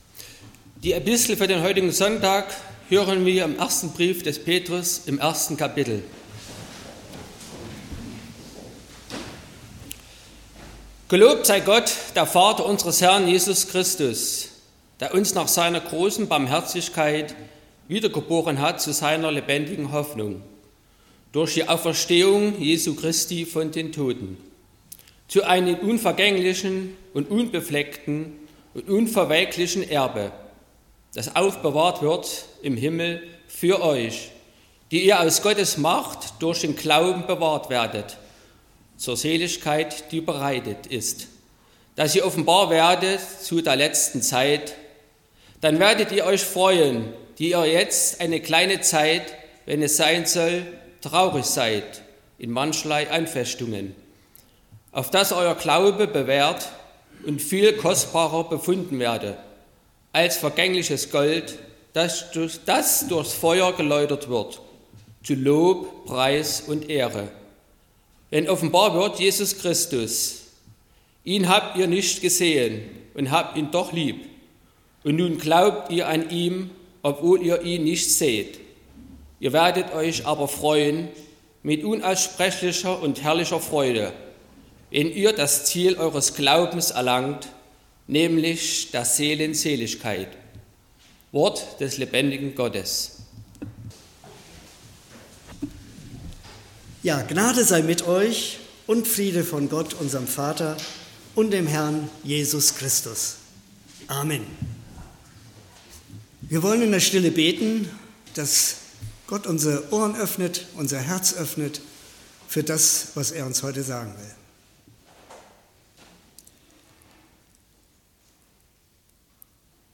04.05.2025 – Gottesdienst
Predigt und Aufzeichnungen